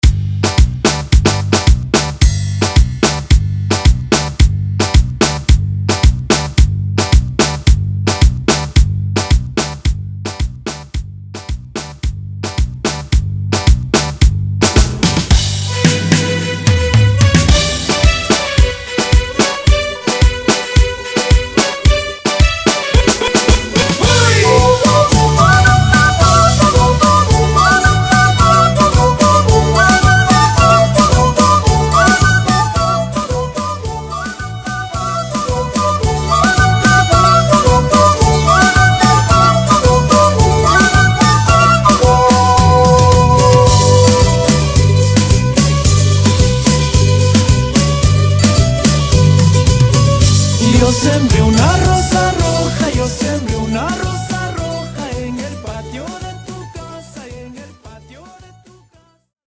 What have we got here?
Etiqueta: Foklore